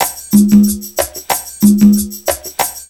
PERCULOOP -R.wav